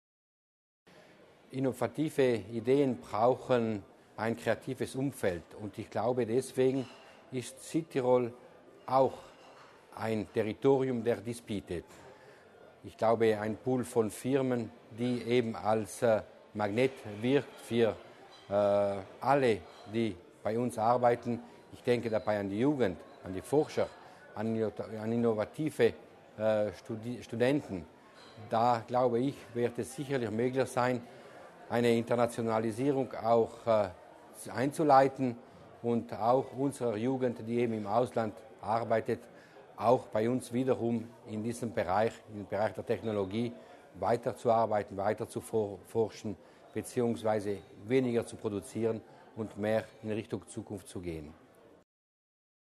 Landesrat Mussner erklärt das Projekt